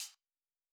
Hihat 2 Wilshire.wav